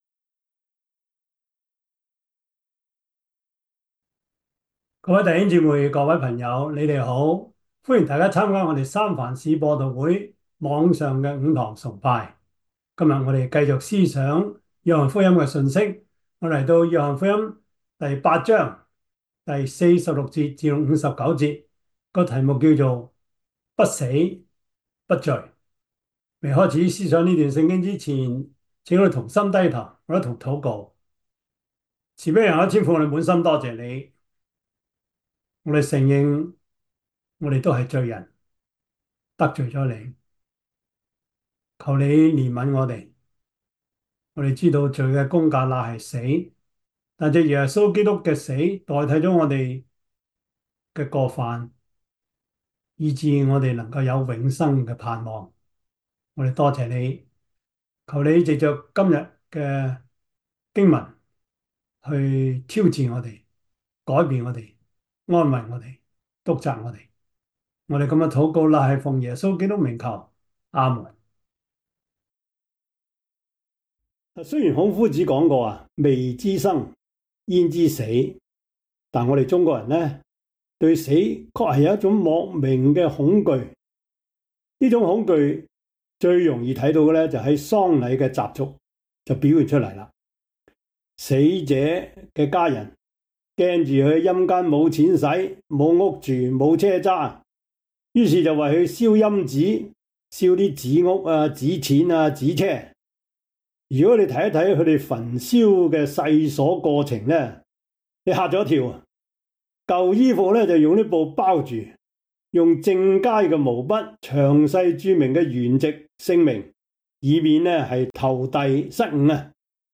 約翰福音 8:46-59 Service Type: 主日崇拜 約翰福音 8:46-59 Chinese Union Version
Topics: 主日證道 « 快樂家庭之點止咁簡單 第五十一課: 基因改造 »